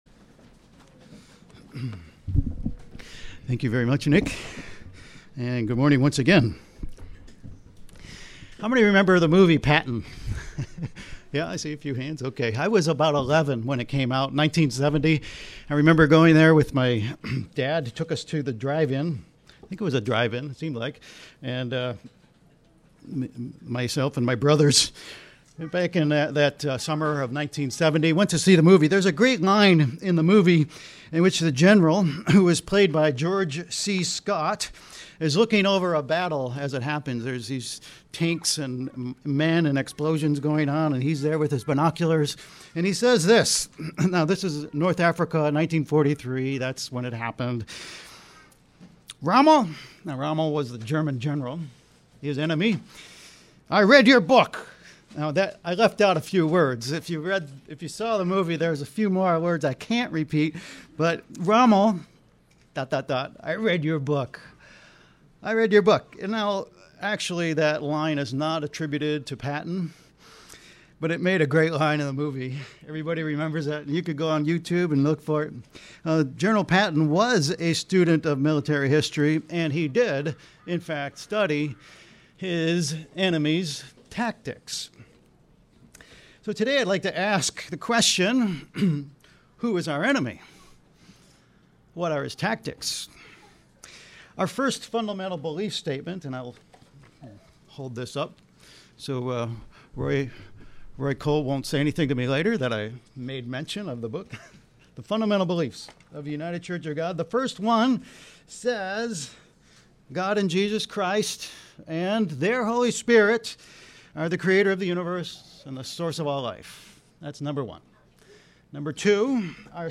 This sermon tells you about your adversary, your accuser, Satan the Devil. It tells you who he is, what he does, and how not to fall prey to his tactics.